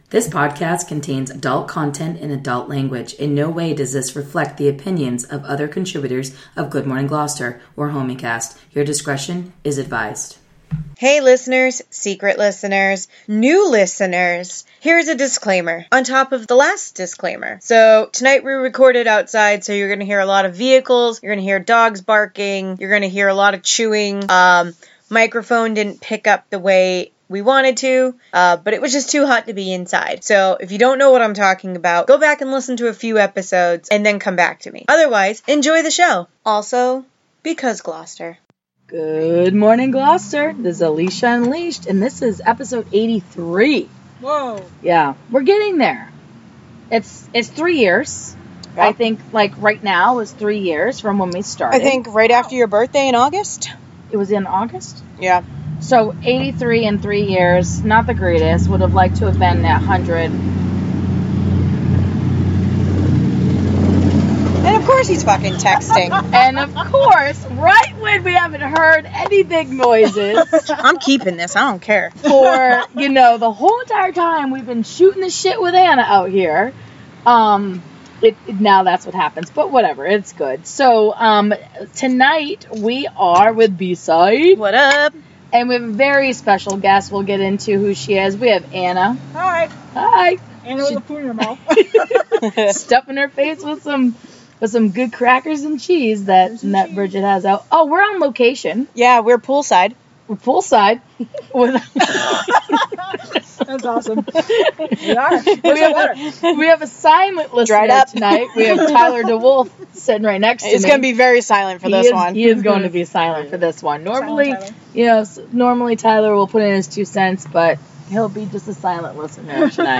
We talk about so many things and so many circles. Dogs bark, neighbors talking, rando’s with loud mufflers.